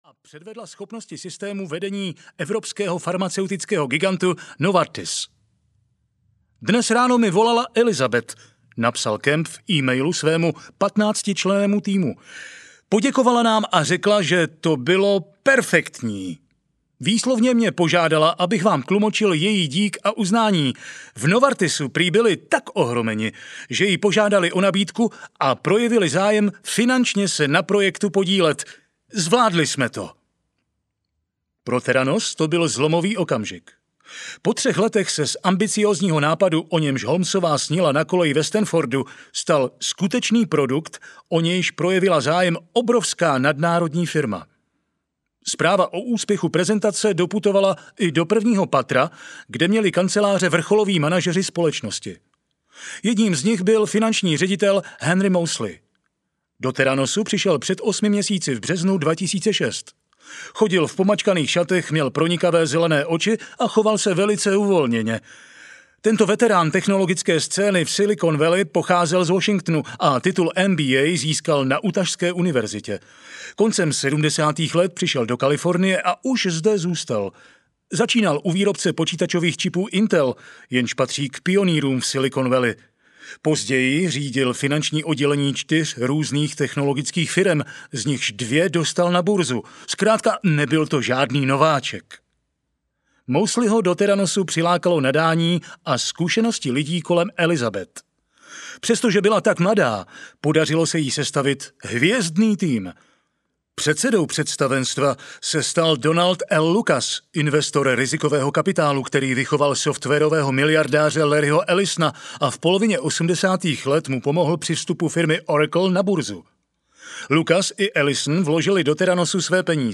Zlá krev audiokniha
Ukázka z knihy